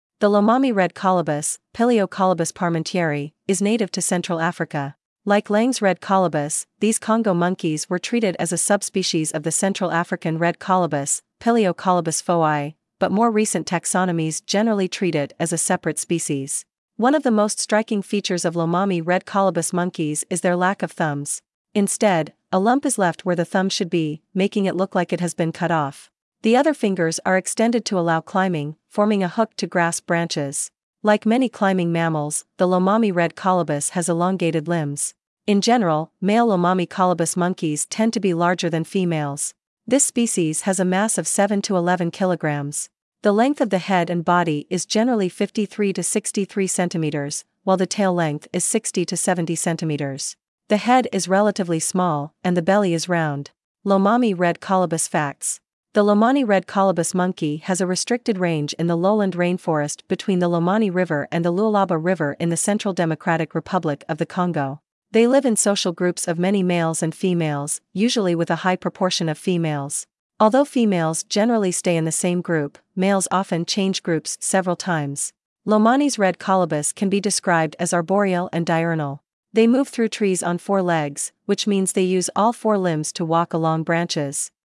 Lomami Red Colobus
Lomami-red-colobus.mp3